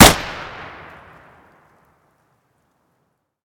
Tommy Gun Drop / gamedata / sounds / weapons / thompson